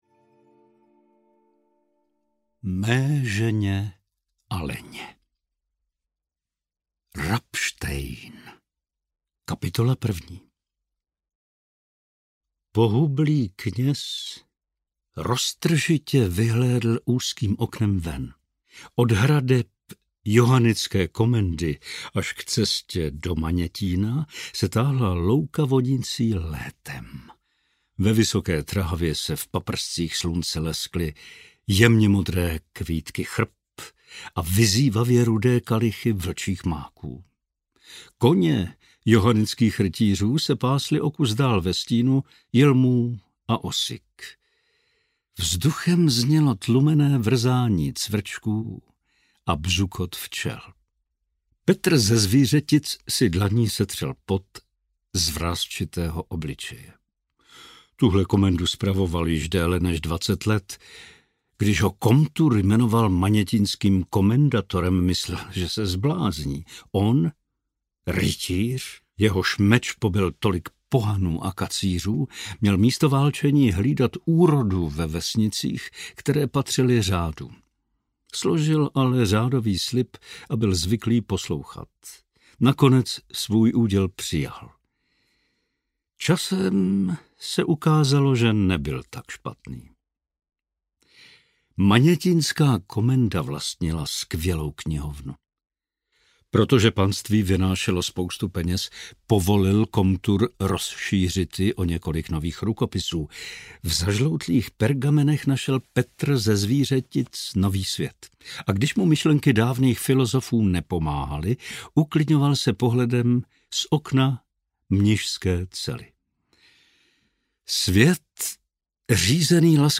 Prodavači ostatků I. audiokniha
Ukázka z knihy
• InterpretPavel Soukup